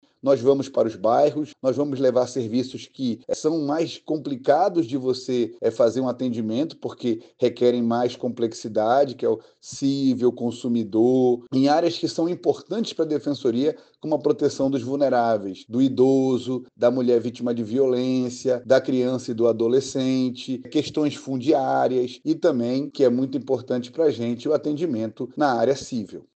Conforme o Defensor Público Geral, dessa forma, a população terá acesso a mutirões mais abrangentes, onde poderá resolver diversas questões jurídicas no próprio atendimento — indo além das áreas de Família e Registros Públicos, que tradicionalmente predominam nesses eventos.